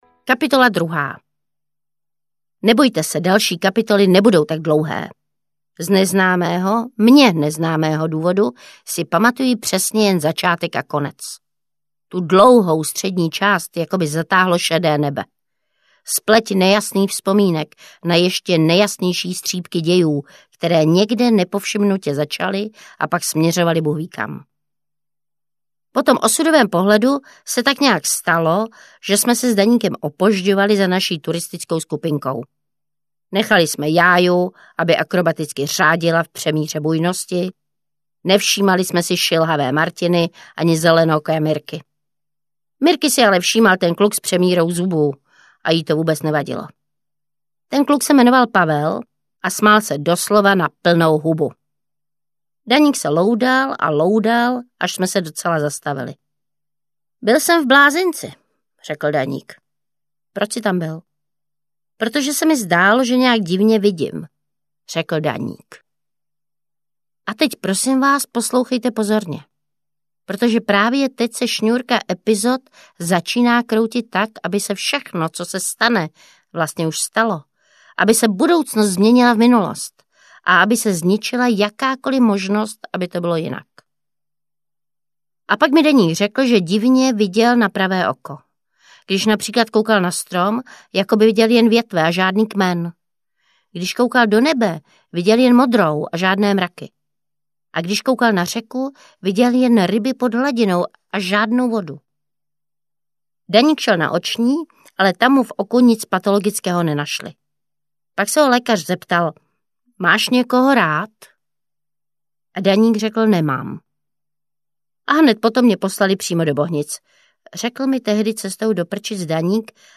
Pravda o mém muži audiokniha
Ukázka z knihy